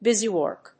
アクセント・音節búsy・wòrk, búsy‐wòrk